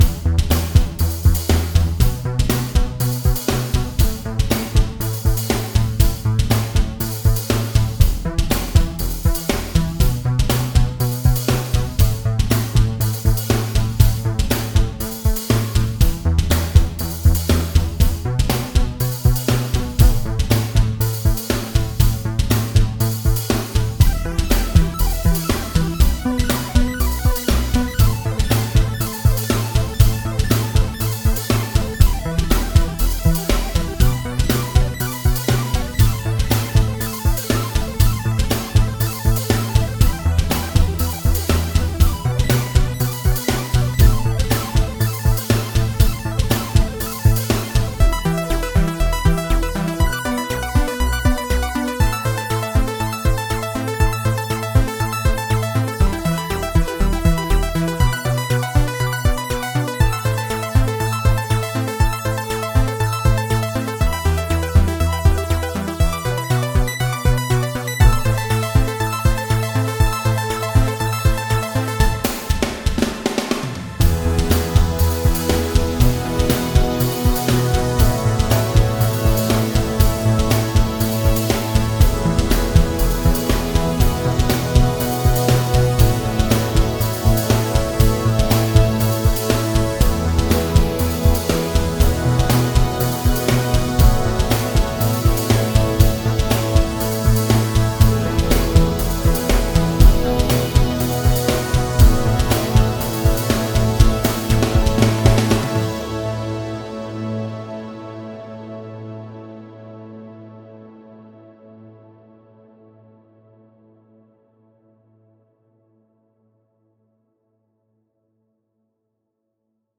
Category 🎮 Gaming